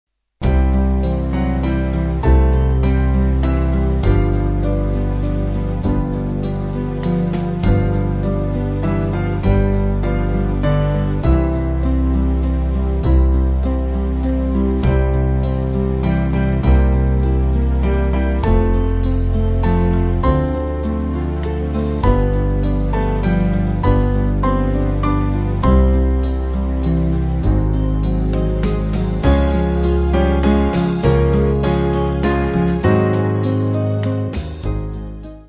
Small Band
(CM)   2/C-Db